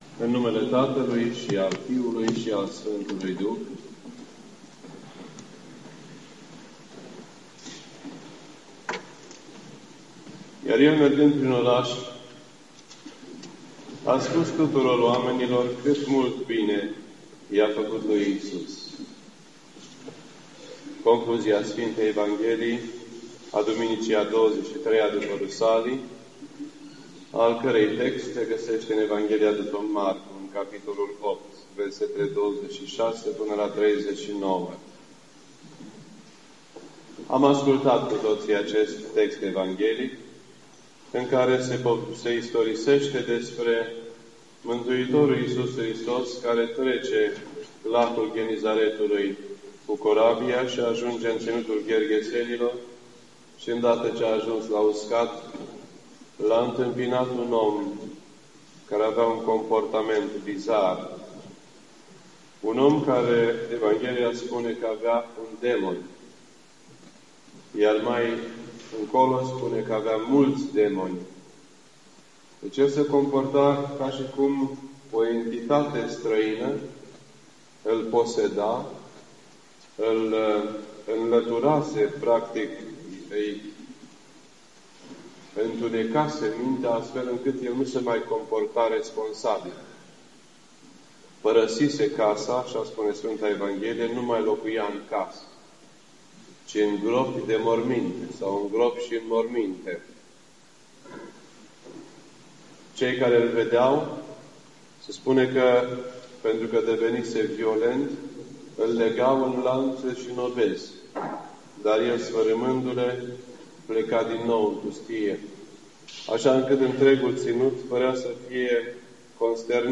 This entry was posted on Sunday, October 30th, 2011 at 7:22 PM and is filed under Predici ortodoxe in format audio.